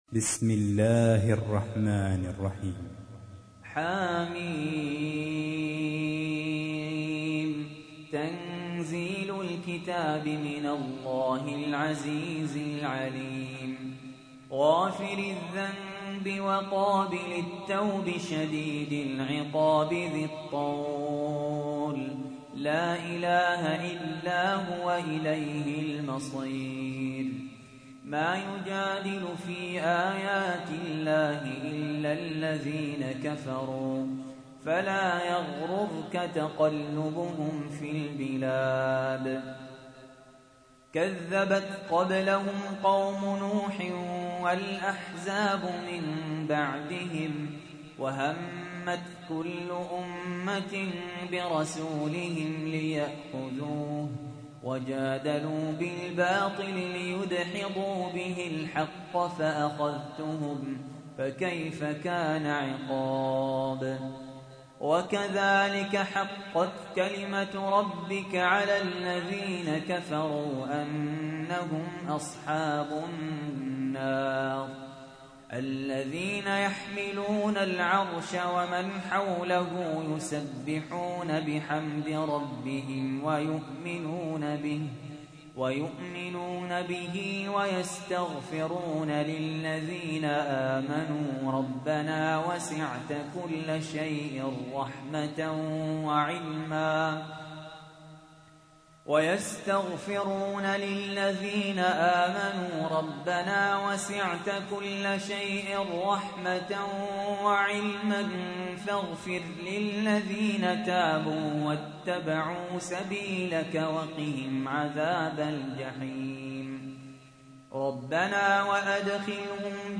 تحميل : 40. سورة غافر / القارئ سهل ياسين / القرآن الكريم / موقع يا حسين